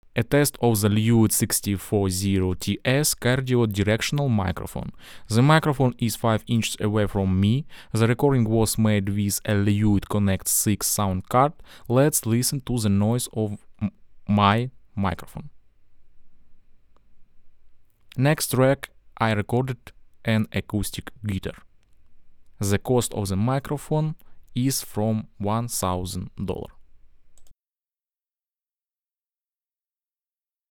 The sound of the Lewitt LCT 640 TS is open, detailed and surprisingly accurate.
The recordings are unprocessed, at the same Gain level.
Lewitt LCT 640 TS – cardioid: